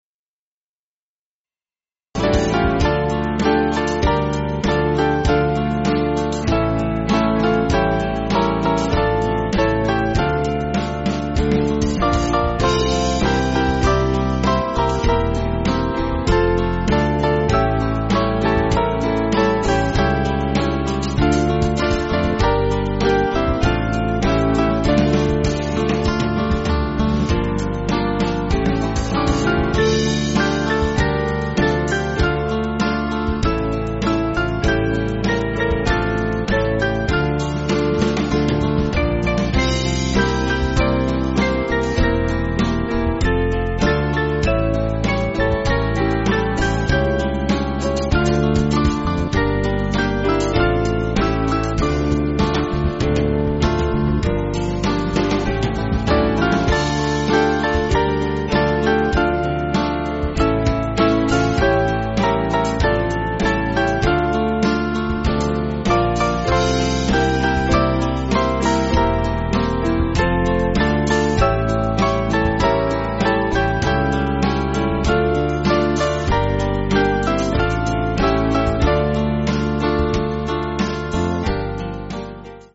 Small Band
(CM)   3/Dm